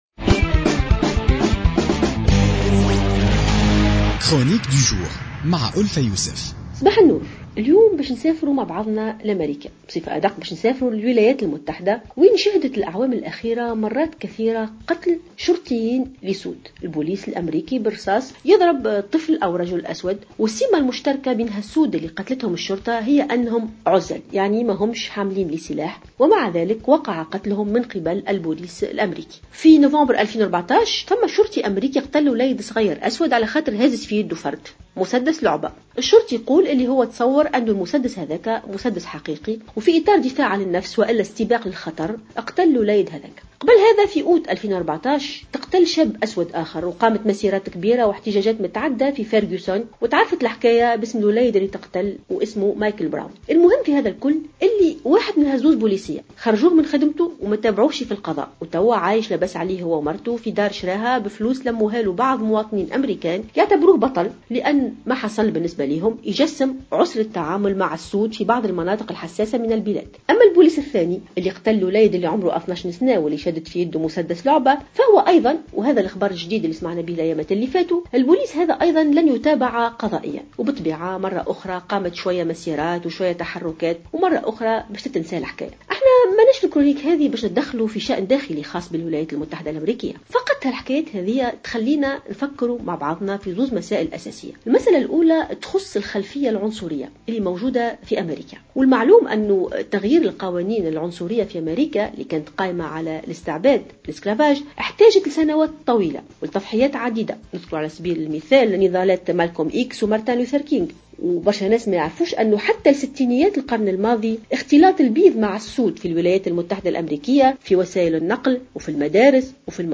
تحدثت الأستاذة الجامعية والباحثة ألفة يوسف في افتتاحية اليوم الثلاثاء 09 فيفري 2016 عن الخلفية العنصرية ضد السود في أمريكا وأبعادها في كل مكان في العالم.